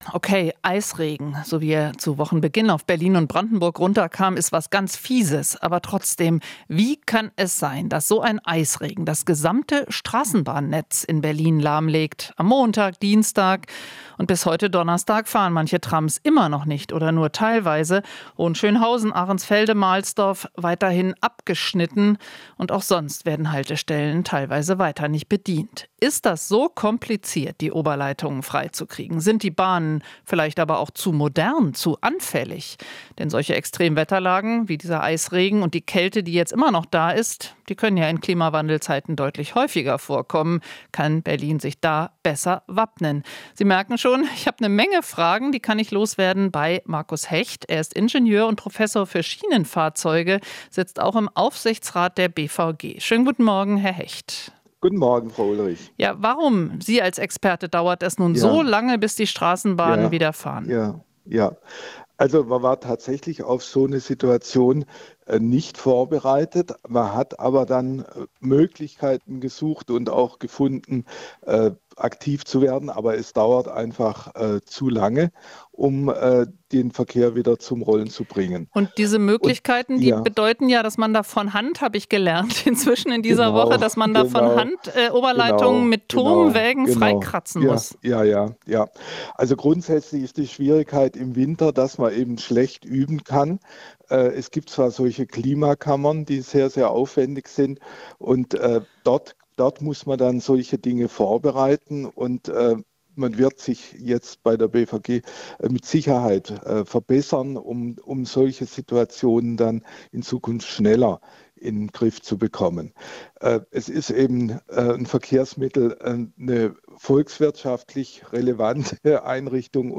Ingenieur: BVG braucht automatische Methode, um Straßenbahnen flott zu kriegen